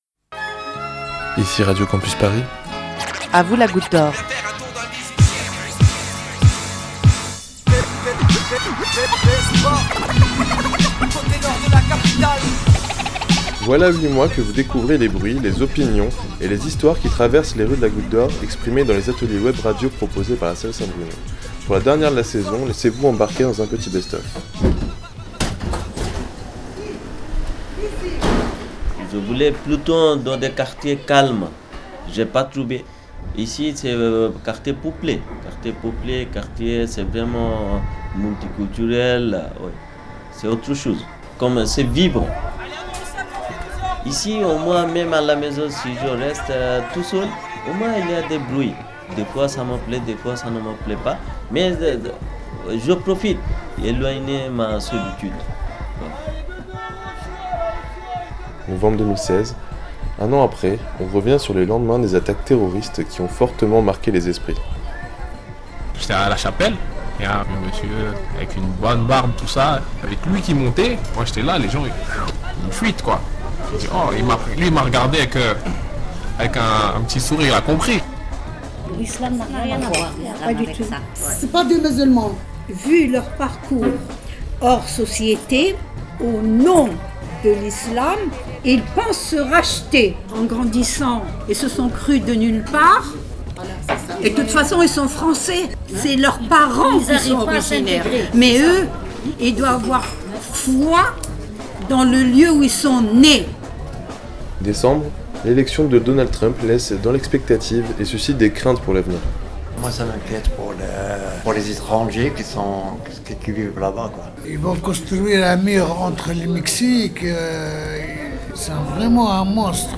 Alors aujourd'hui, au son du marché de Barbès, sous le métro aérien, vous pourrez réentendre quelques extraits des sept dernières pastilles d'À Vous la Goutte d'Or, qui nous ont emmenées des lendemains des attentats parisiens aux élections présidentielles, en passant par des sujets sur le logement, l'engagement ou les discriminations.